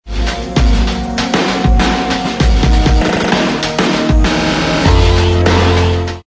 Флейтовые средства агогики и артикуляции/+Трактат по флейте (мультифоники)